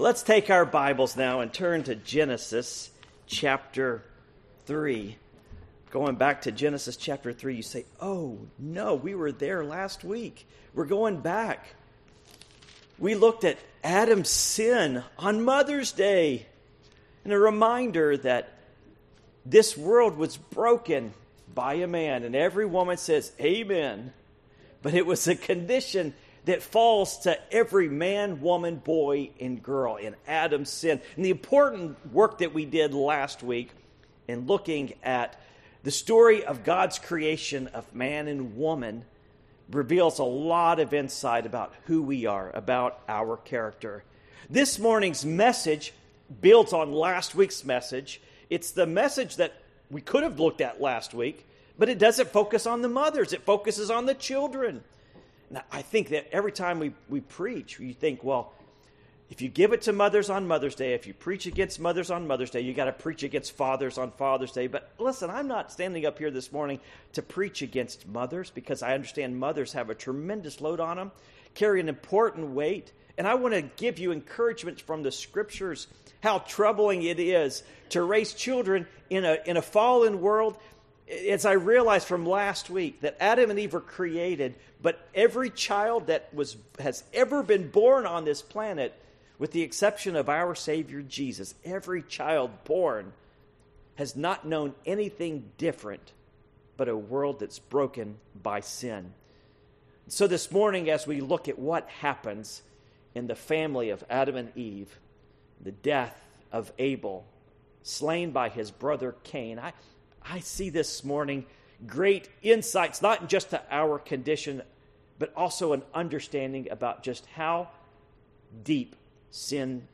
Passage: Genesis 4:1-15 Service Type: Morning Worship